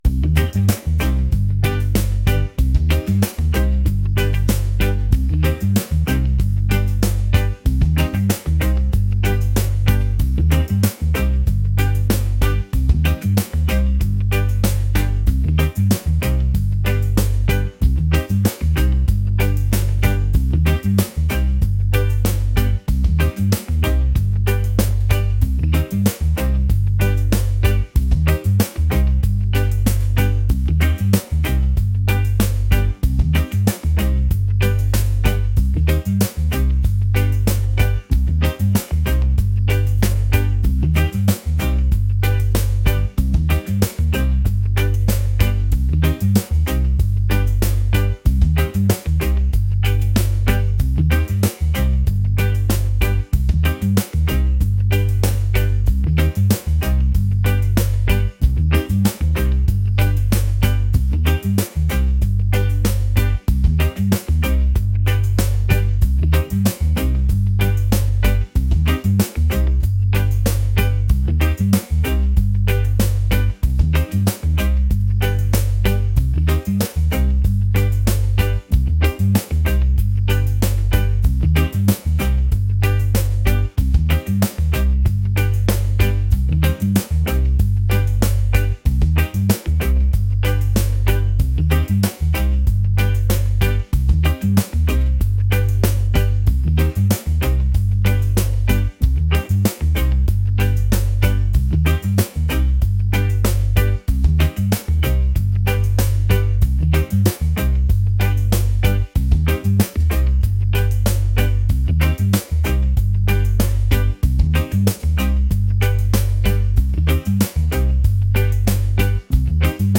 reggae | upbeat | rhythmic